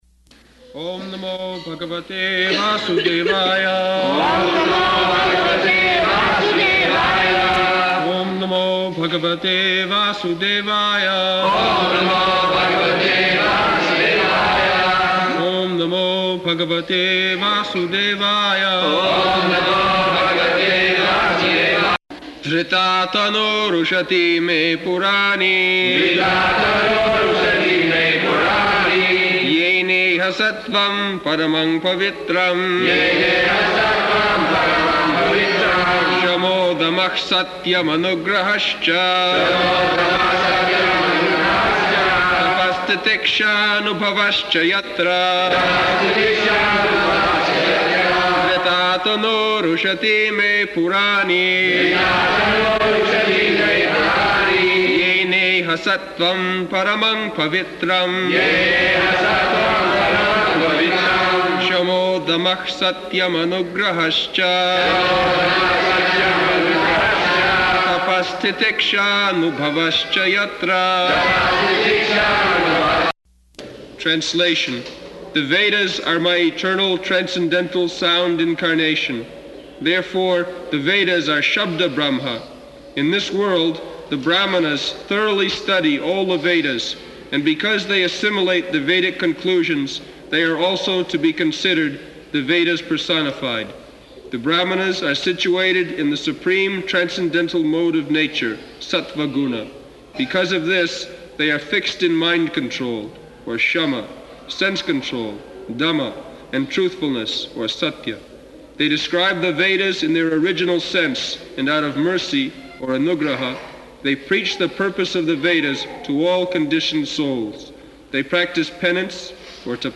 November 11th 1976 Location: Vṛndāvana Audio file
[devotees repeat] [leads chanting of verse, etc.]